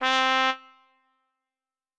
Cow_Life_Sim_RPG/Sounds/SFX/Instruments/Trumpets/doot1.wav at e69d4da15373a101a490e516c925cbcdf63458a3
doot1.wav